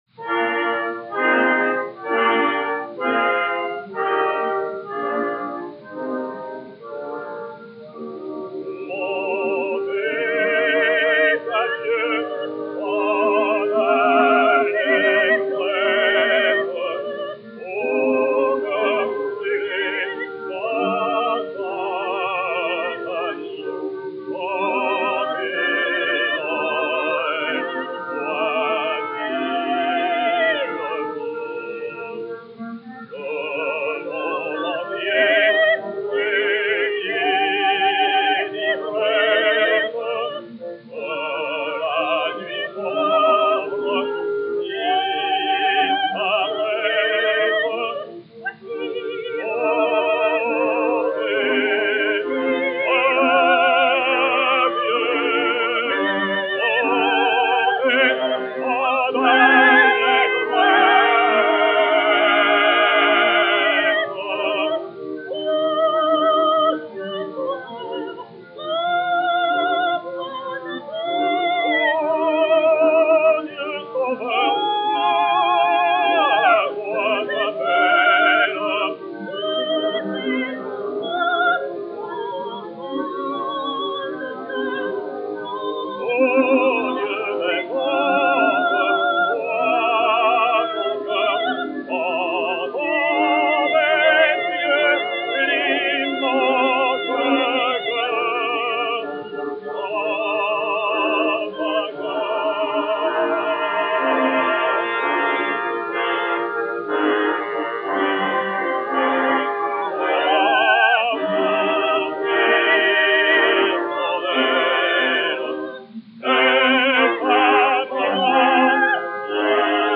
Mélodie pour soprano et contralto
enr. à Paris en 1909